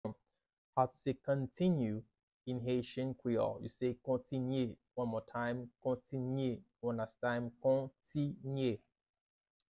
How to say “Continue” in Haitian Creole – “Kontinye” pronunciation by a native Haitian teacher
How-to-say-Continue-in-Haitian-Creole-–-Kontinye-pronunciation-by-a-native-Haitian-teacher.mp3